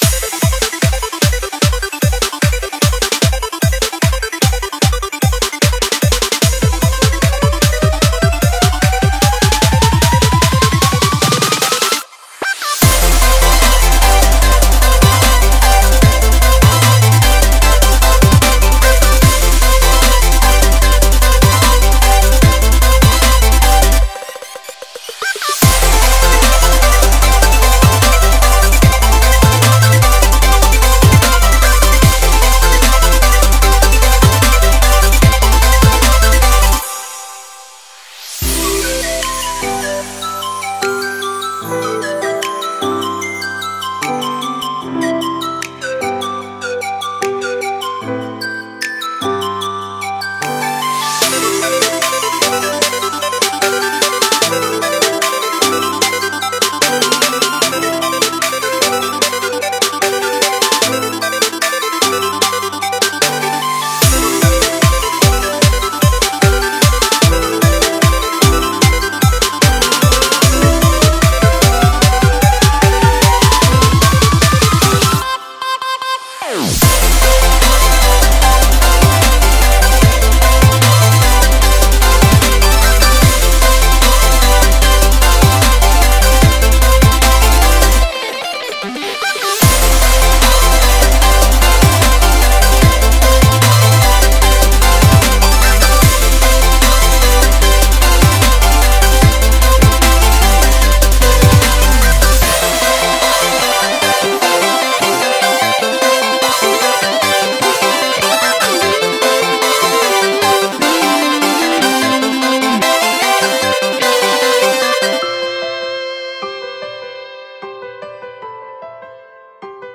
BPM75-150
MP3 QualityMusic Cut